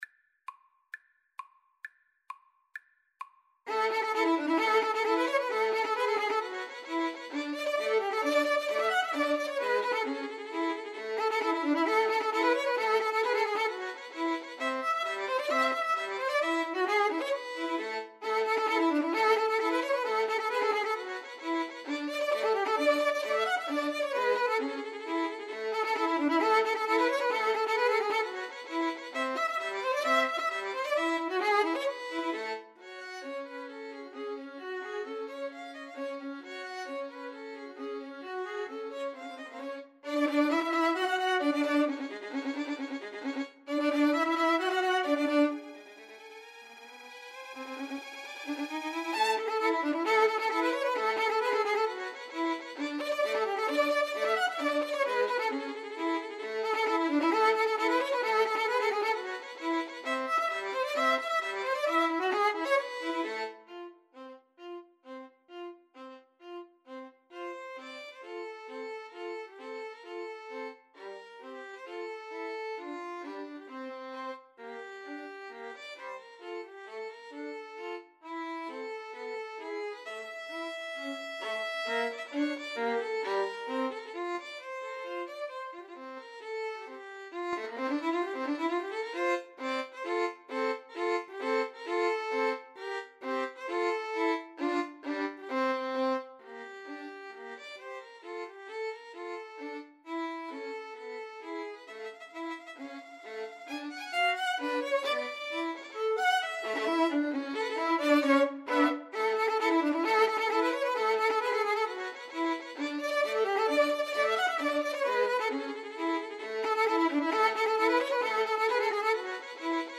2/4 (View more 2/4 Music)
Violin Trio  (View more Intermediate Violin Trio Music)
Classical (View more Classical Violin Trio Music)